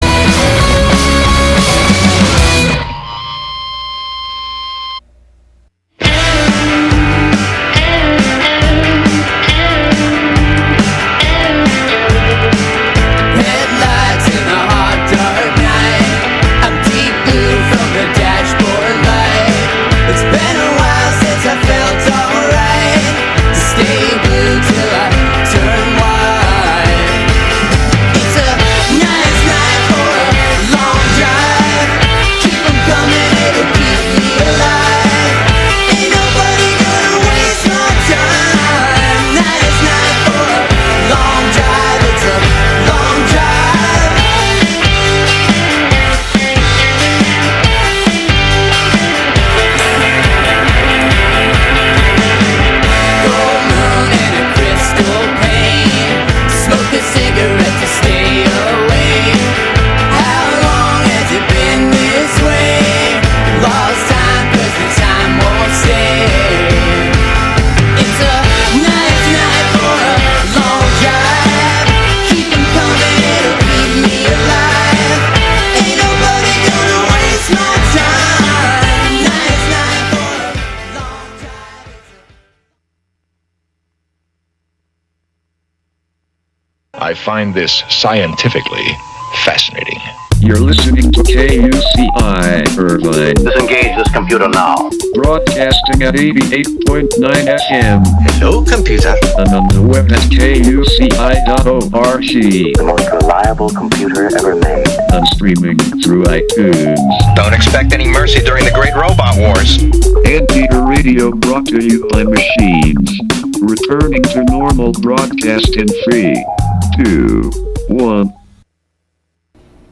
Huricane Harvey Full Interview (2).mp3